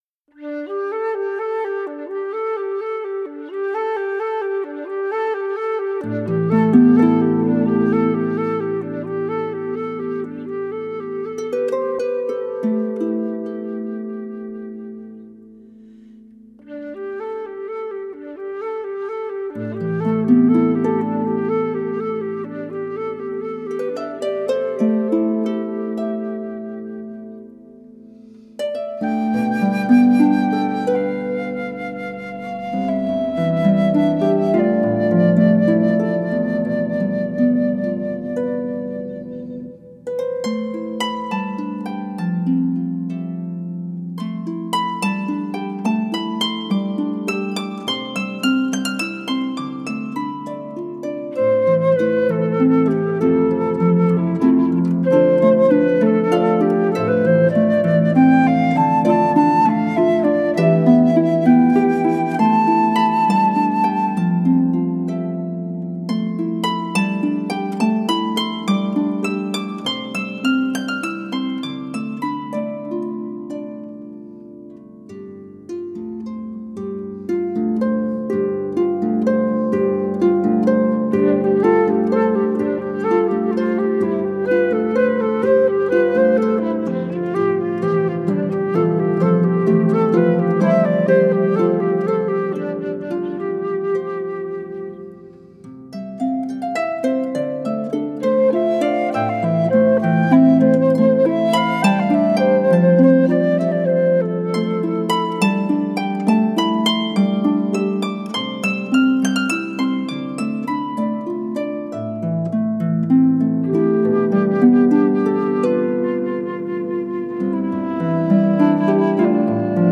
is for flute and lever or pedal harp